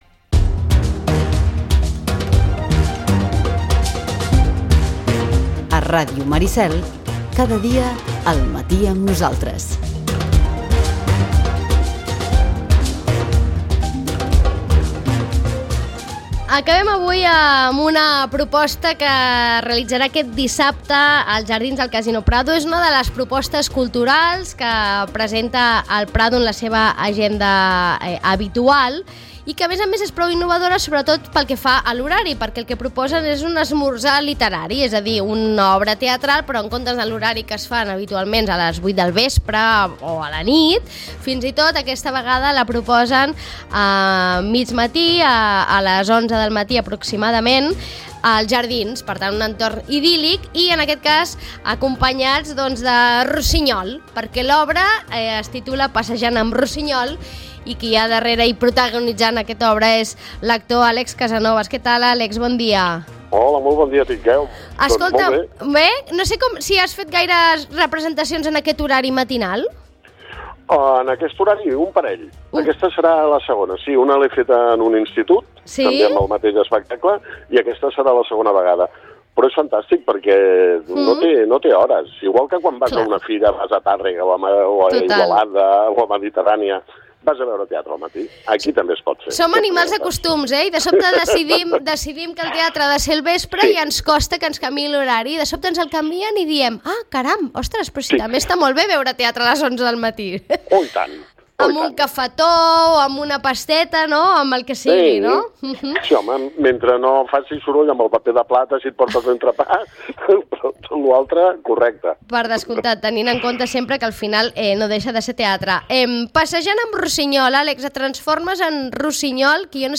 Hem parlat amb Àlex Casanovas de la vigència dels textos i pensaments de Rusiñol en tant que protagonista de l'obra